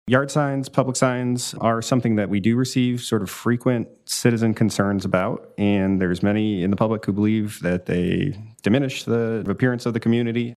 City Councilman Chris Burns says he welcomes the new rules.